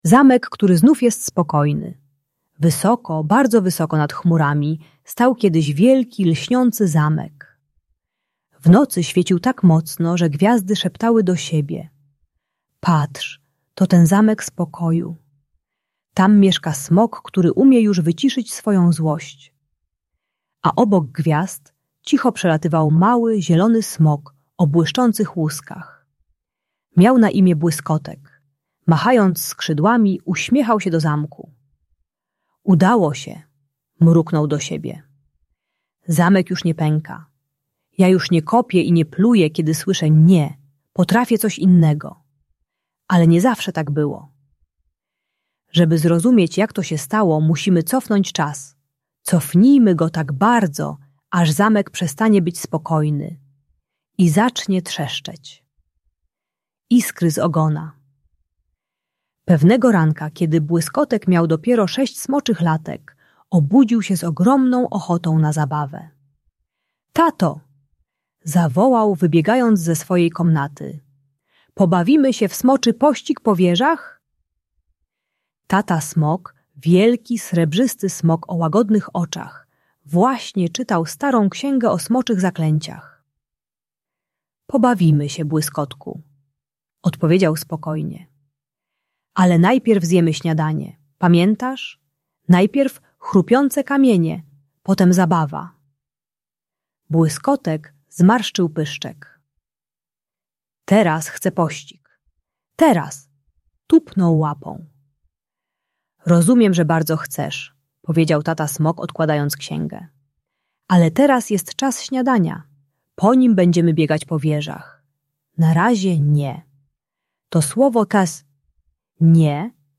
Błyskotek i Zaklęcie Spokoju - Agresja do rodziców | Audiobajka